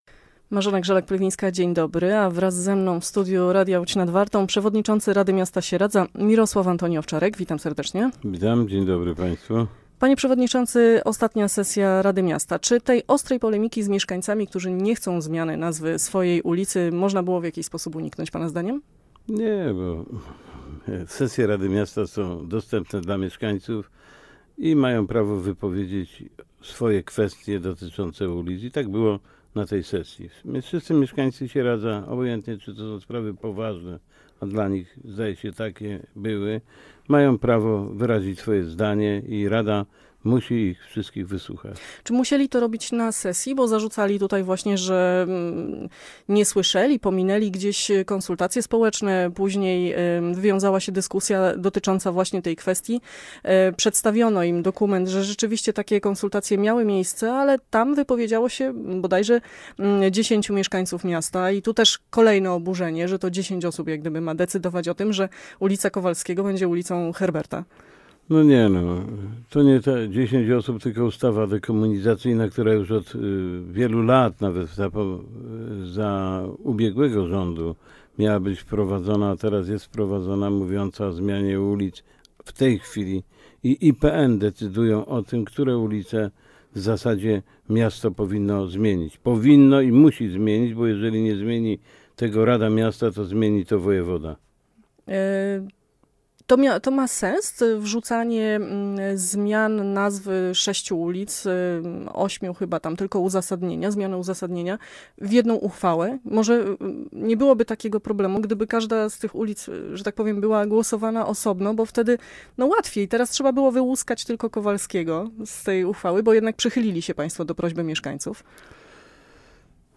Gościem Radia Łódź Nad Wartą był przewodniczący Rady Miasta Sieradza, Mirosław Antoni Owczarek.